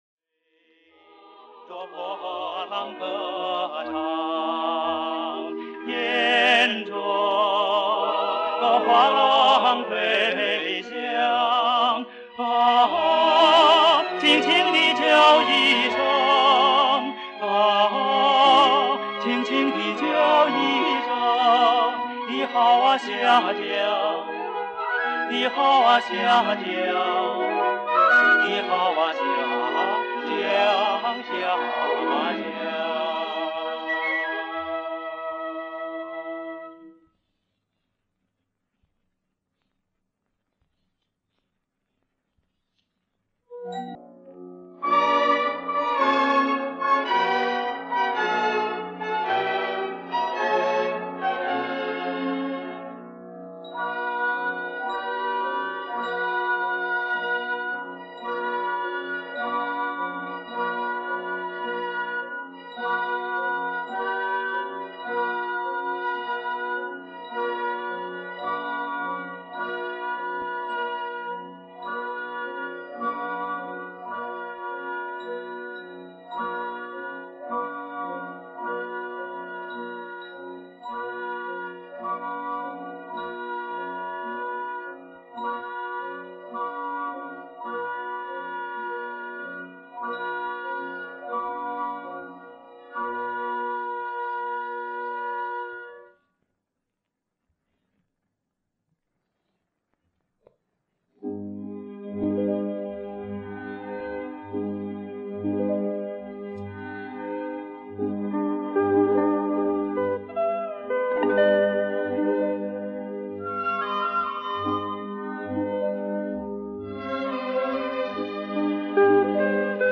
这应该是电视片的原声版本。
二重唱
女高音独唱
四个音轨截取了一部分供试听